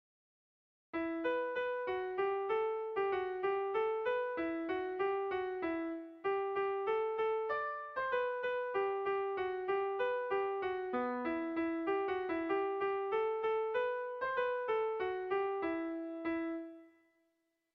Erromantzea
ABD